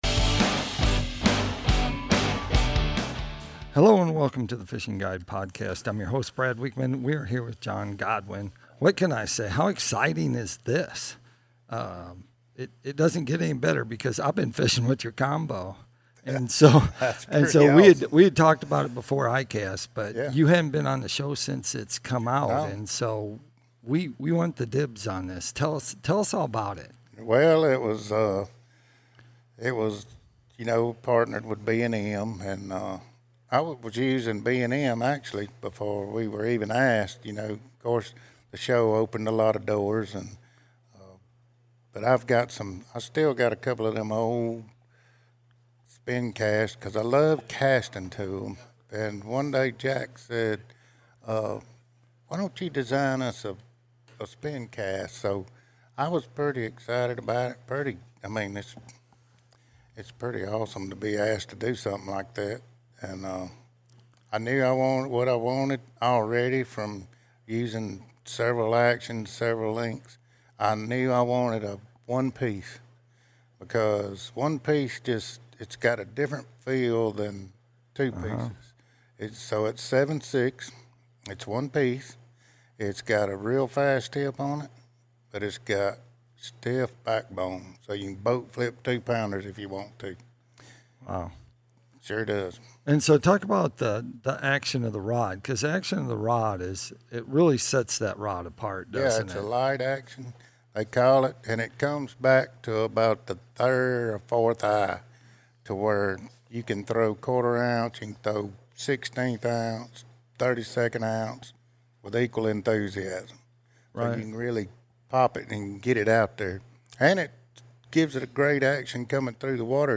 interviews crappie fishing guide and Duck Dynasty star John Godwin.